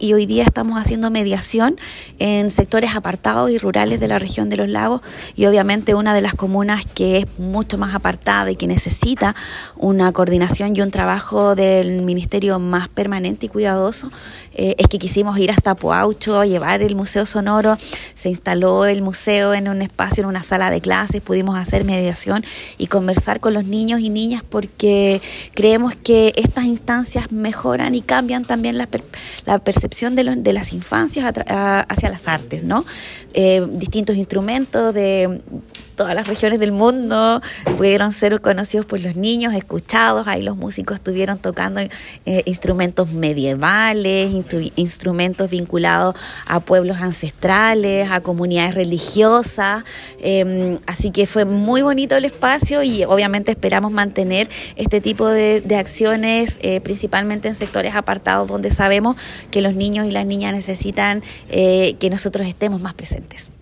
La Seremi de las Culturas, Cristina Añasco, explicó que este tipo de actividades tienen como objetivo acercar la cultura y el arte a las comunidades más alejadas, asegurando que todos los sectores, especialmente las zonas rurales, tengan acceso a propuestas educativas y culturales de calidad.
11-noviembre-24-cristina-anasco-intervencion.mp3